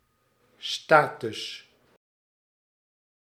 Ääntäminen
IPA : /ˈʃeɪp/ US : IPA : [ʃeɪp]